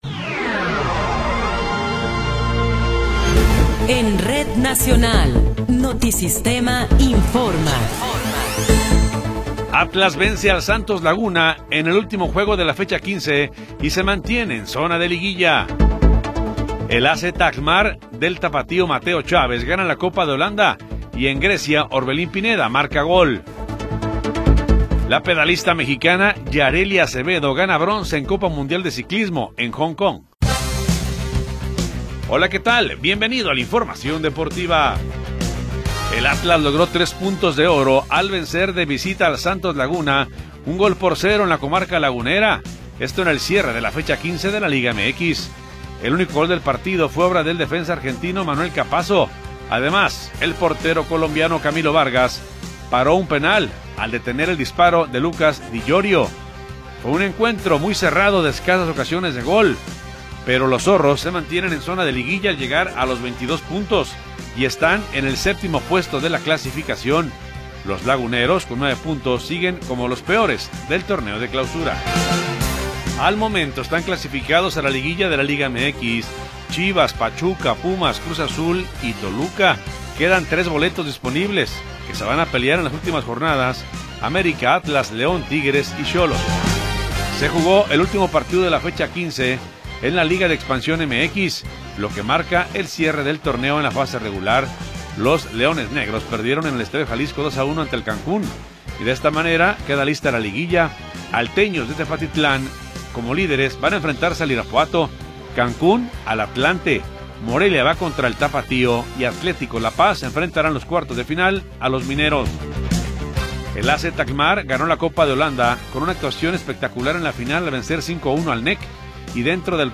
Noticiero 20 hrs. – 19 de Abril de 2026